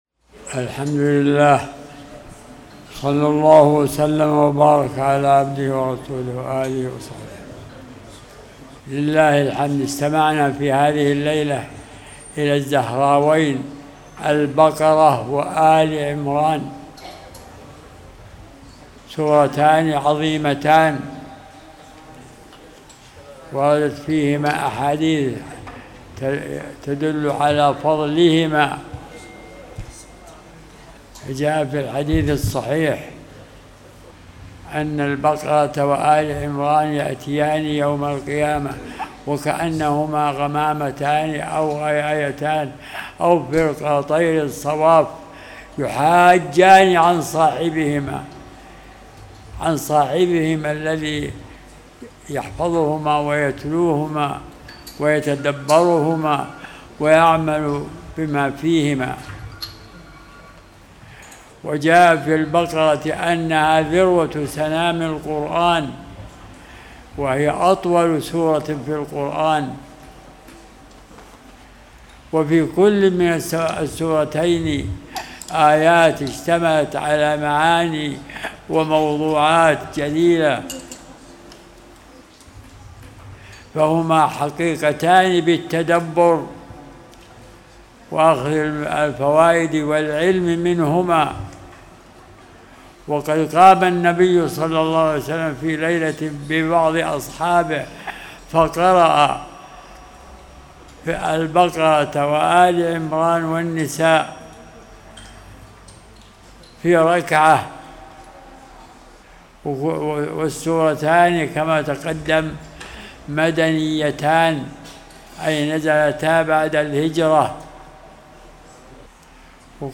(43) فوائد مستنبطة من سور القرآن - الزهراوين : البقرة وآل عمران (استراحة صلاة القيام في رمضان 1447)